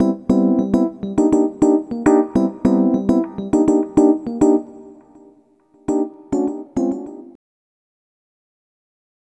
Ala Brzl 1 Piano-A.wav